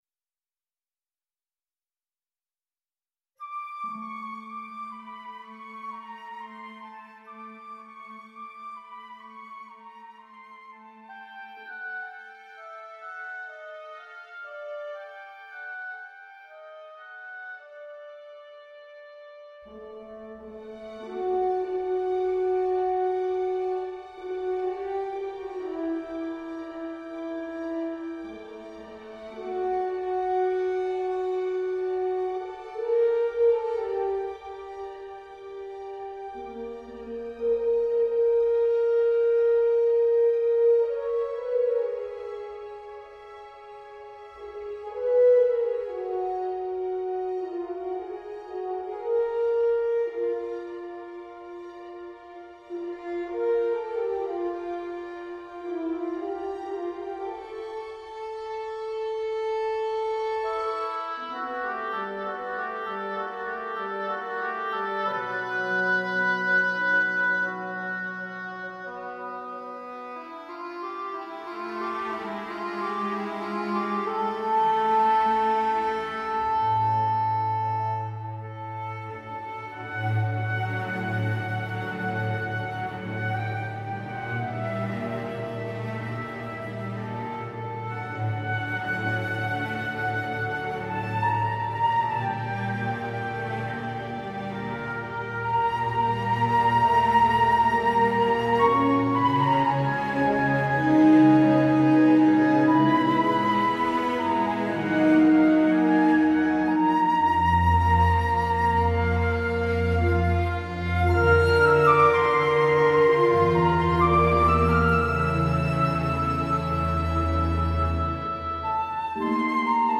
(Secuenciado partiendo de la partitura original).